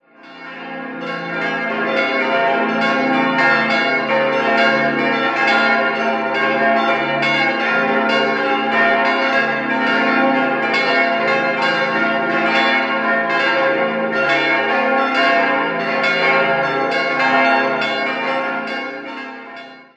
6-stimmiges erweitertes Wachet-auf-Geläute: des'-f'-as'-b'-des''-f'' Die Glocken des', as' und des'' von 1881 stammen aus der Gießerei Becker in Ingolstadt, die f' wurde 1960 von Czudnochowksy gegossen, die b' (1976) und f'' (1981) von Perner.